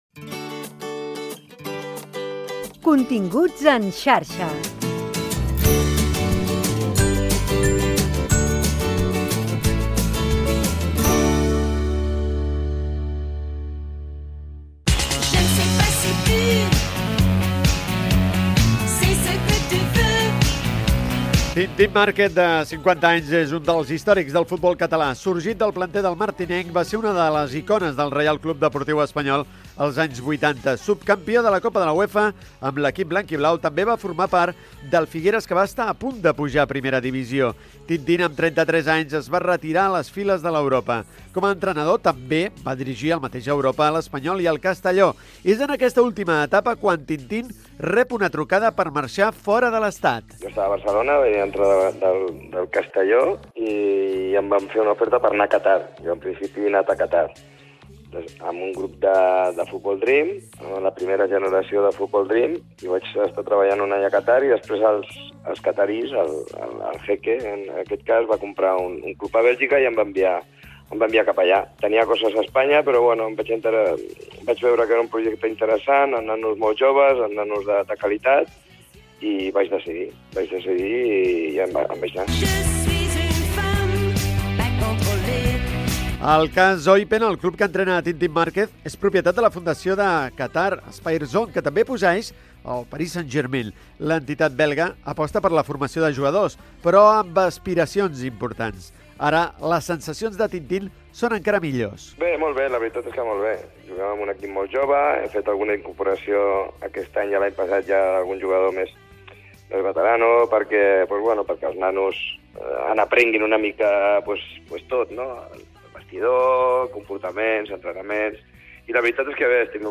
Indicatiu del programa, fragment d'una entrevista al jugador de futbol i entrenador Tintín Márquez. En aquell moment estava entrenat un equip a la segona divisió de futbol a Bèlgica.